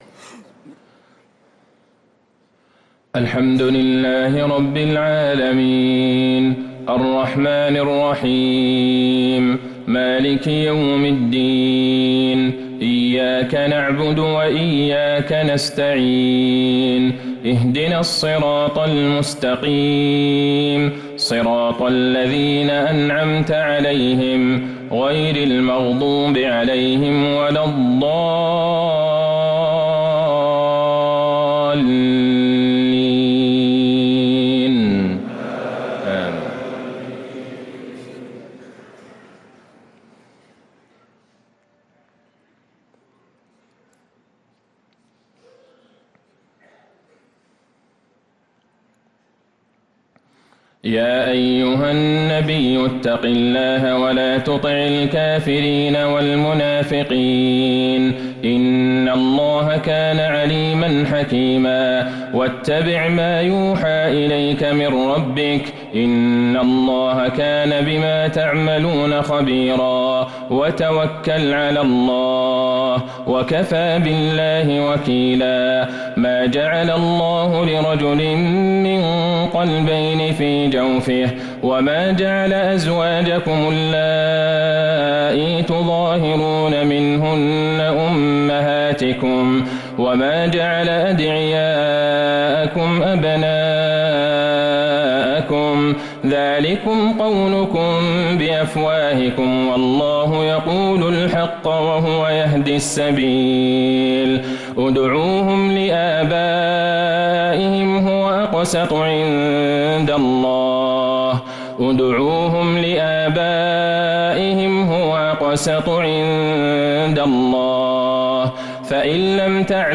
صلاة العشاء للقارئ عبدالله البعيجان 20 ذو الحجة 1444 هـ
تِلَاوَات الْحَرَمَيْن .